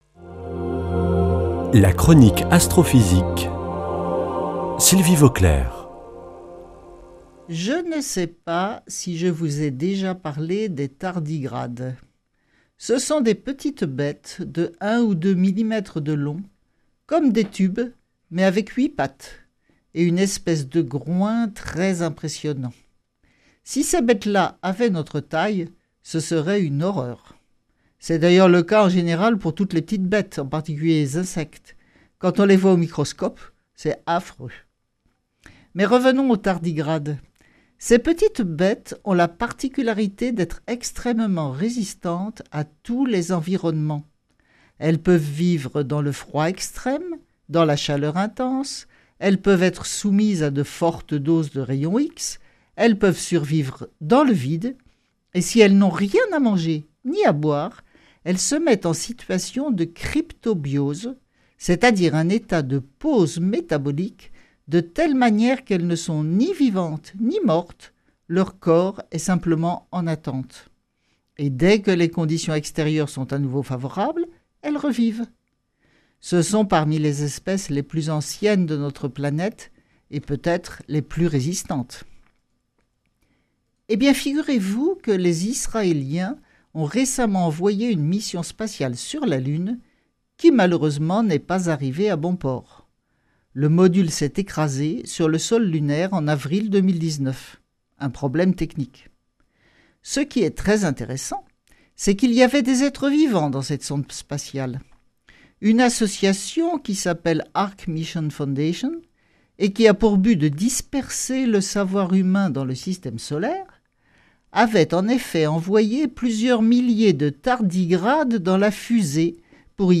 vendredi 10 juillet 2020 Chronique Astrophysique Durée 3 min